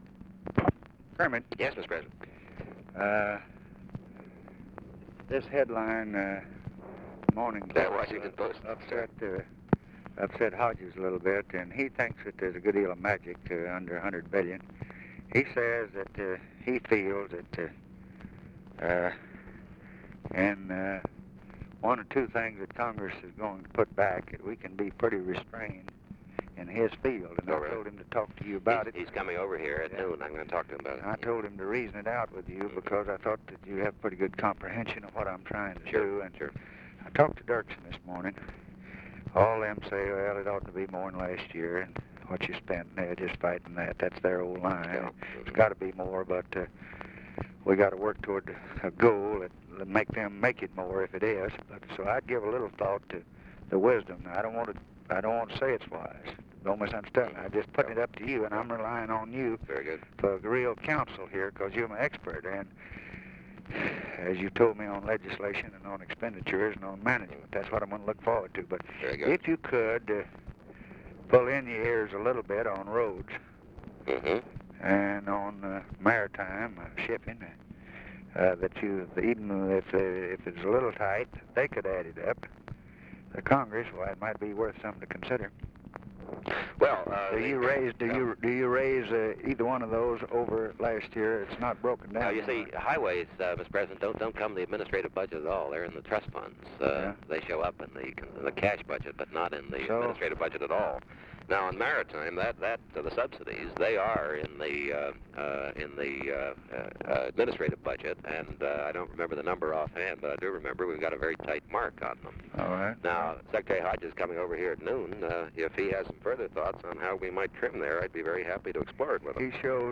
Conversation with KERMIT GORDON, December 4, 1963
Secret White House Tapes